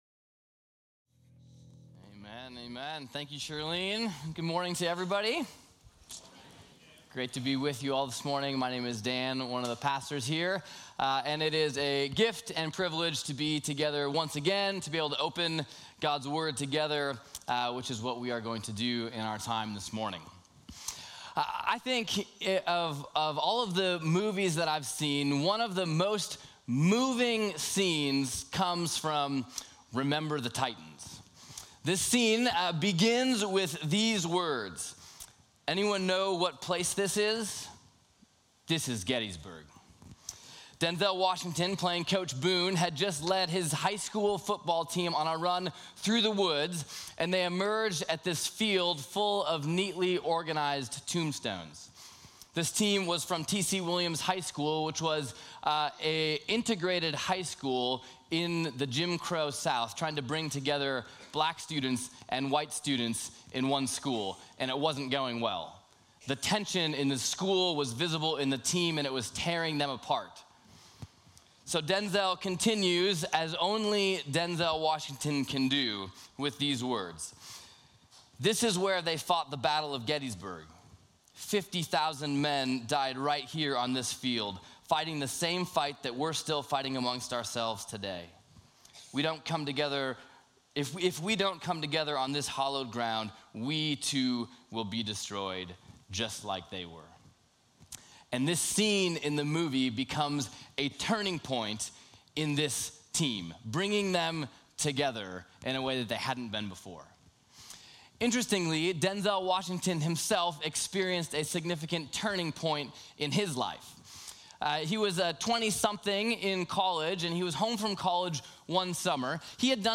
Sermons - Peninsula Bible Church